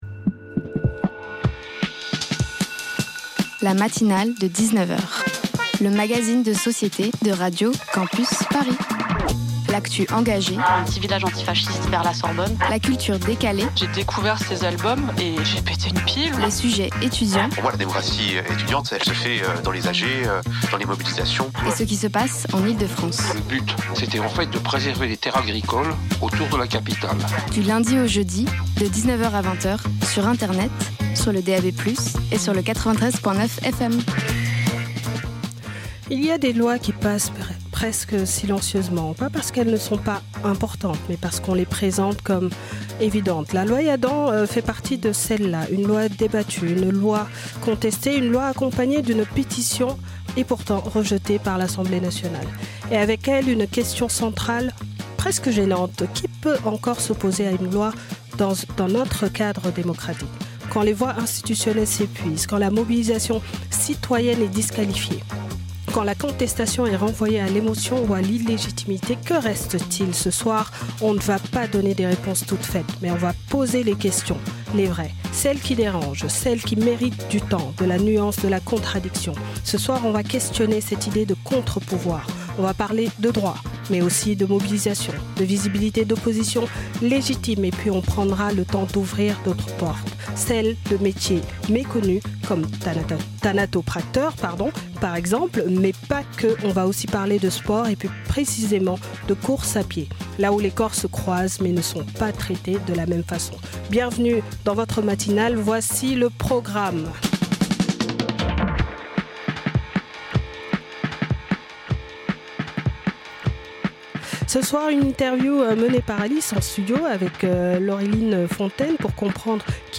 Rejet de la pétition contre la loi Yadan & le métier de thanatopracteur Partager Type Magazine Société Culture jeudi 16 avril 2026 Lire Pause Télécharger Ce soir